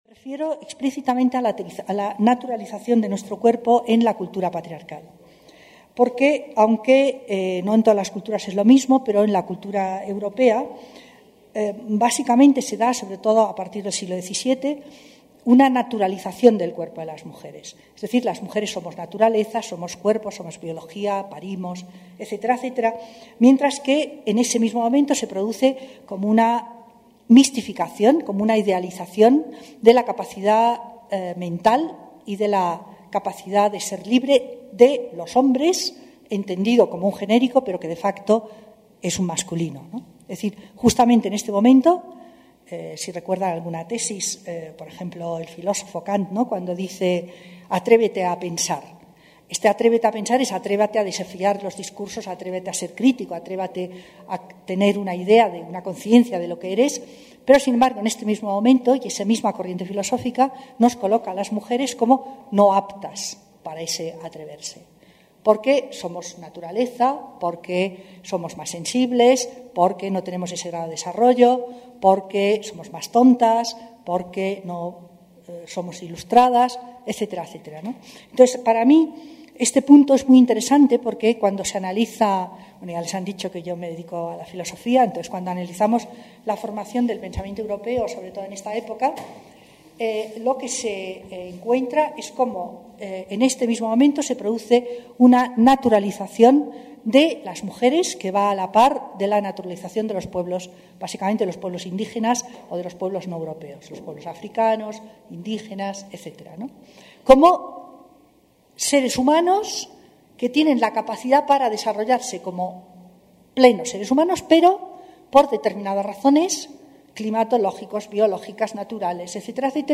El pasado mes de noviembre, FLACSO Ecuador y su Laboratorio de Críticas de la Violencia del Departamento de Sociología y Estudios de Género, desarrolló el ciclo de debates denominado Vida, violencia y aborto: la subjetividad política de las mujeres, en donde a partir de tres días de debate se trataron temas alrededor de: Aborto, vida digna y cuidado; Justicia, violencia sexual y derecho al aborto y Luchas por la despenalización del aborto y subjetividad política de las mujeres.